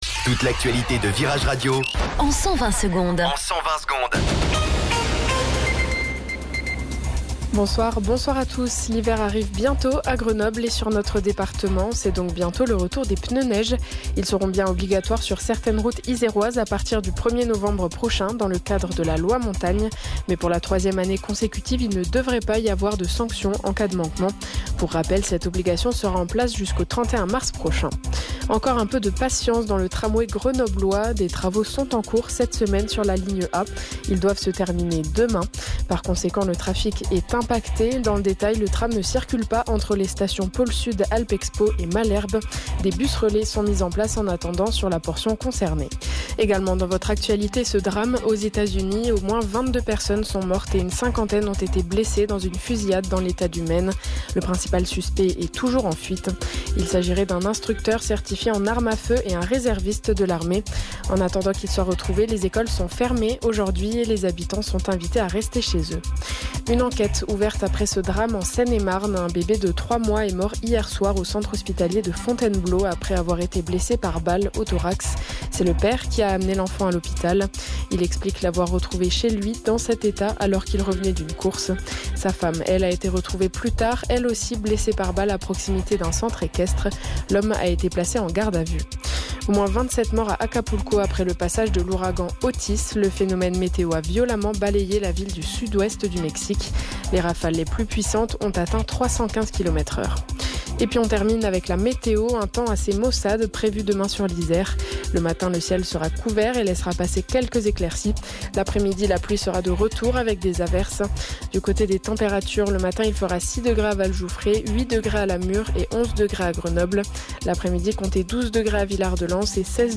Flash Info Grenoble 26 Octobre 2023 Du 26/10/2023 à 17h10 Flash Info Télécharger le podcast Partager : À découvrir The Strokes : Un nouvel album pour dynamiter 2026, REALITY AWAITS !